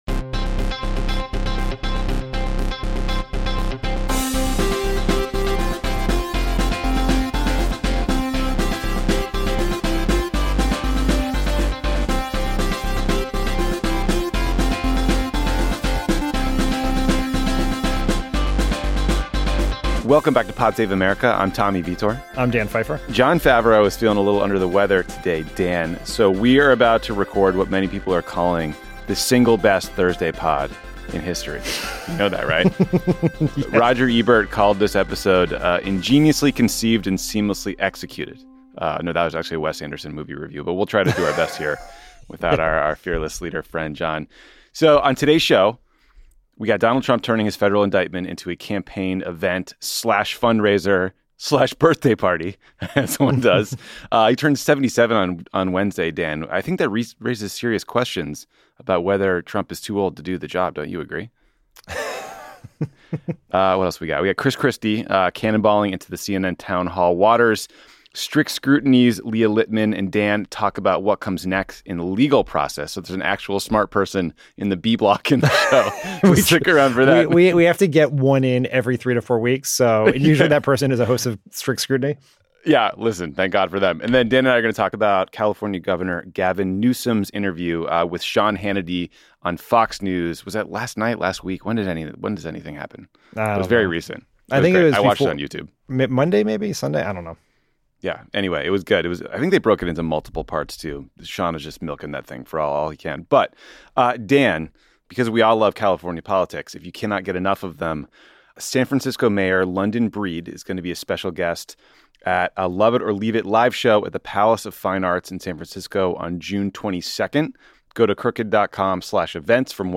And finally, highlights from California Governor Gavin Newsom taking on Sean Hannity on Fox News.